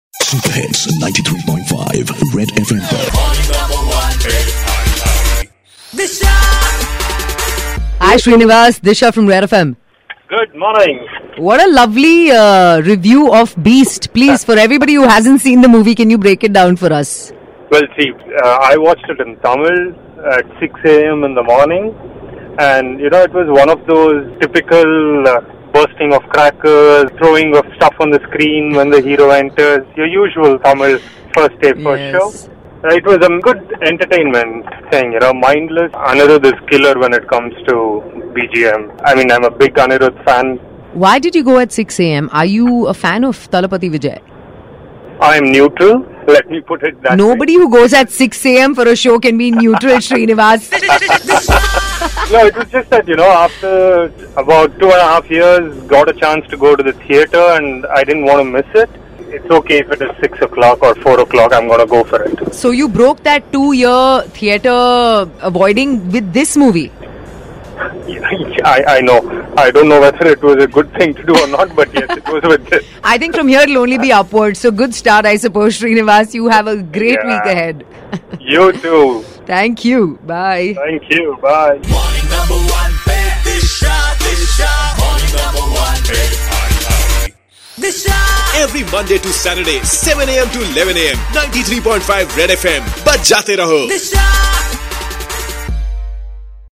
in conversation with listener